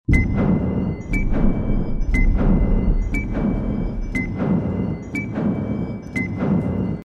读秒声